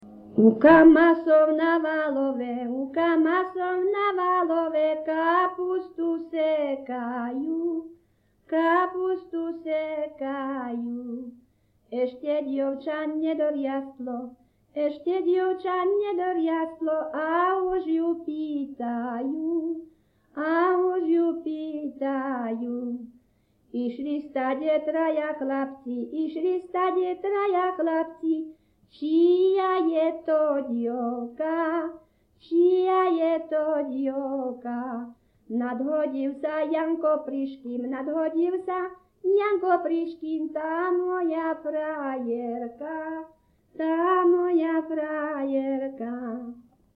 Popis sólo ženský spev bez hudobného sprievodu
Miesto záznamu Litava
Predmetová klasifikácia 11.5. Funkčne neviazané piesne
Kľúčové slová ľudová pieseň